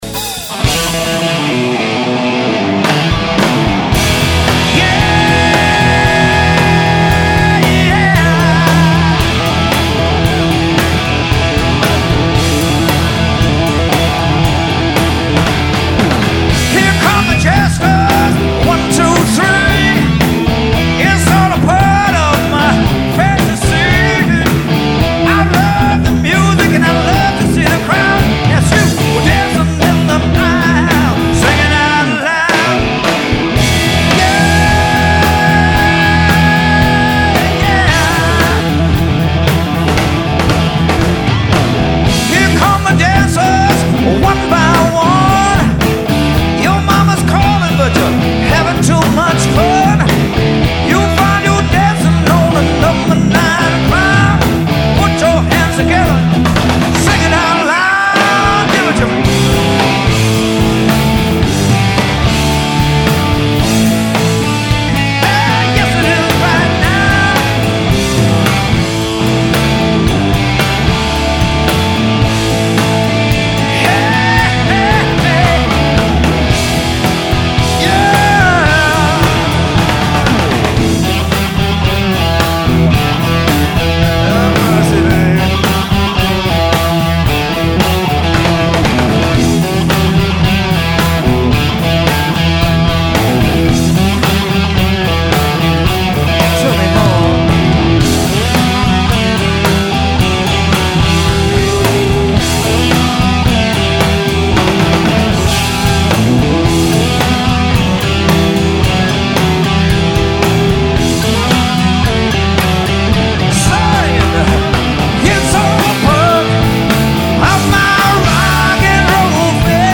completely live, no overdubs